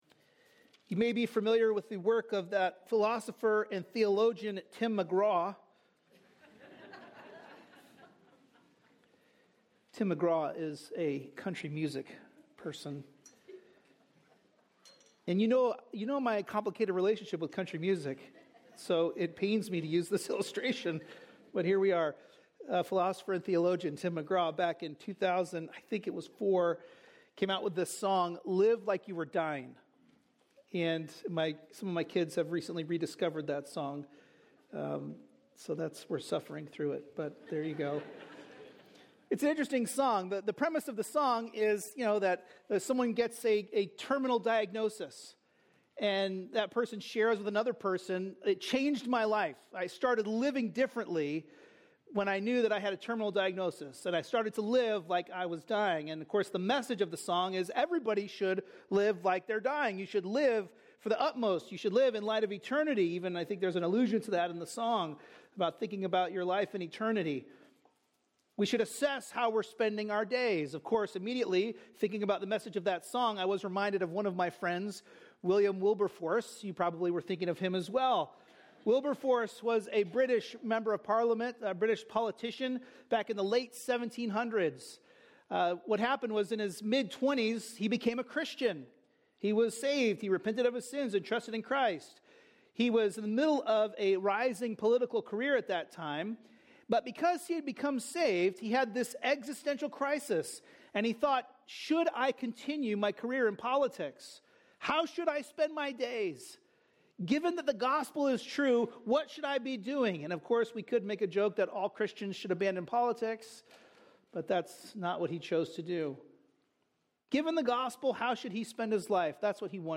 A message from the series "Hebrews." In Hebrews 2:1-9 we learned that the gospel is greater.